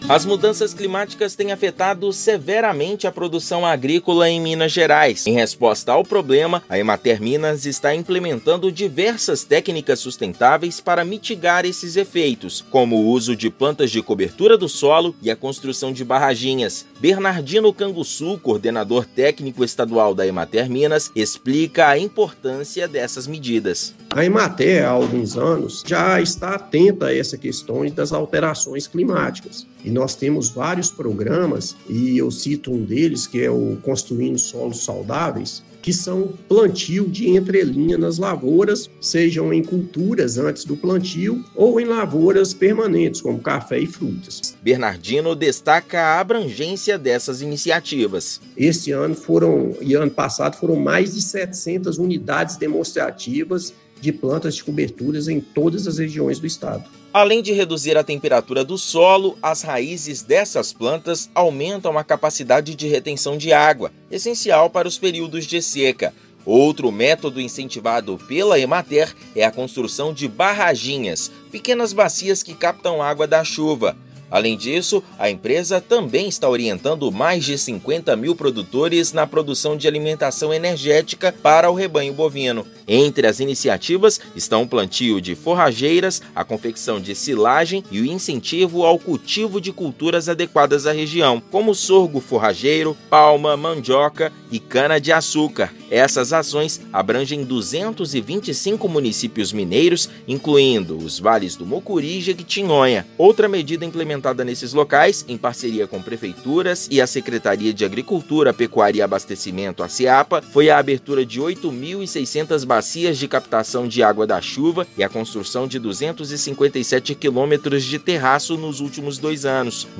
Construção de barraginhas e uso de plantas de cobertura do solo estão entre as recomendações da Emater. Ouça matéria de rádio.